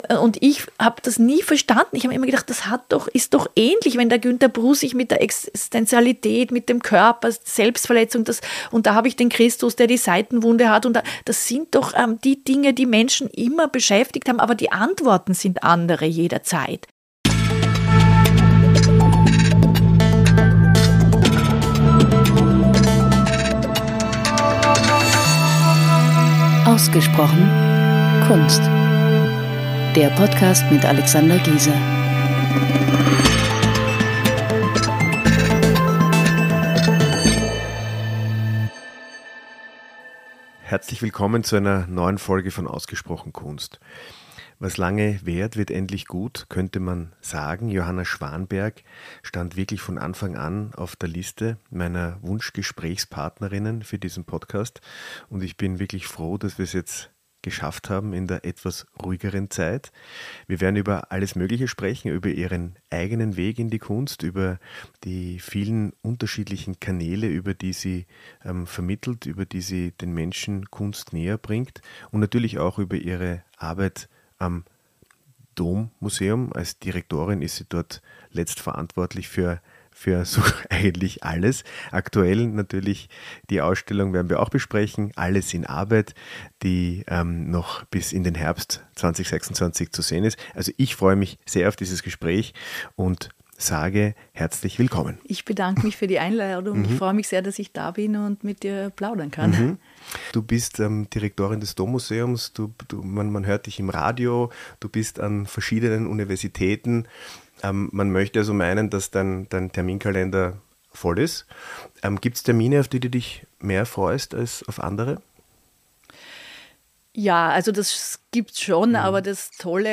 In der vergleichsweisen ruhigen Zeit haben wir uns zusammengesetzt und über das breite Spektrum ihres Tuns gesprochen.